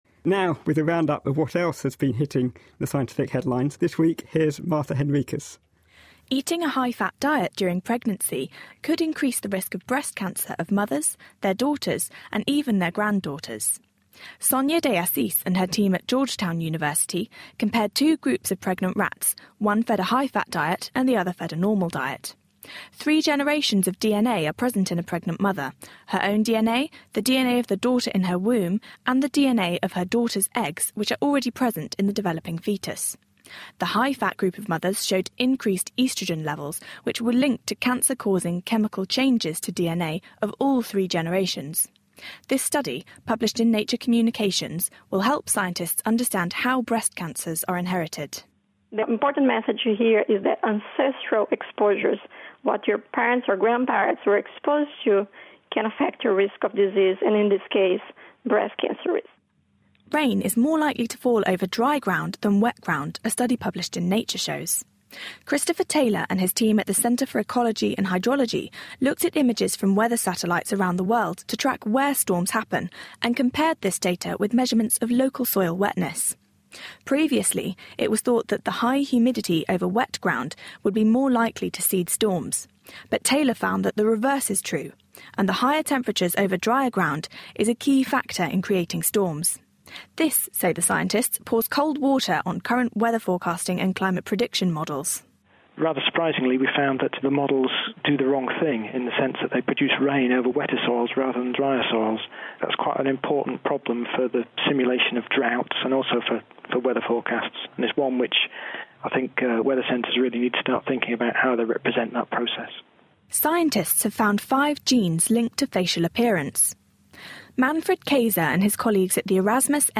Interview with